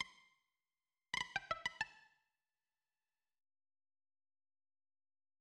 electric guitar (muted)